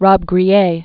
(rôb-grē-yā), Alain 1922-2008.